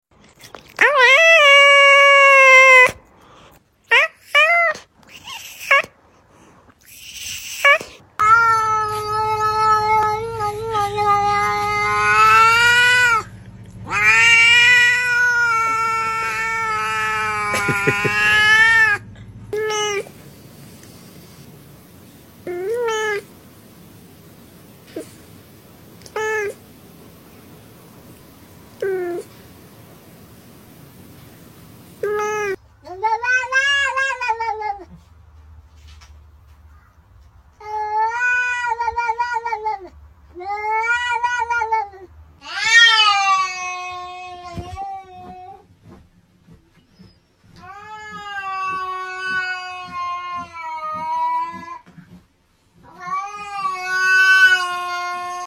Top 5 Funniest Cat Meowing Sound Effects Free Download